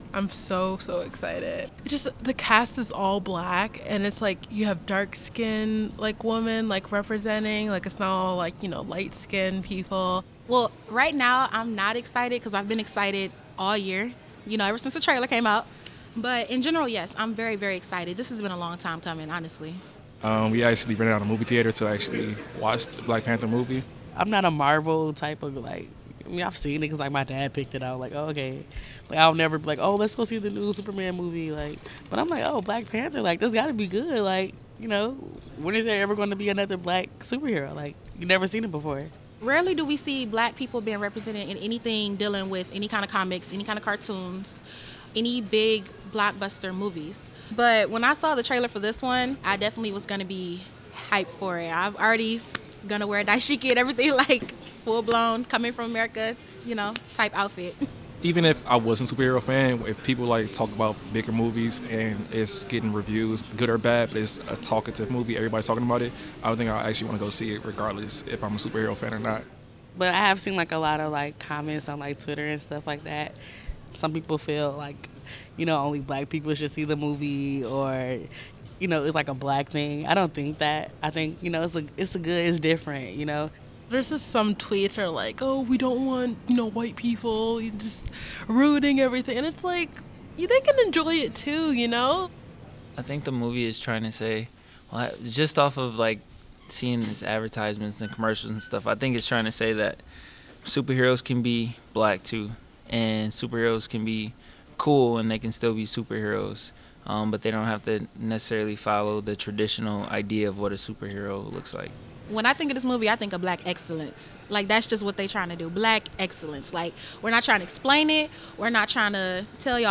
Black-Panther-Vox-pop.wav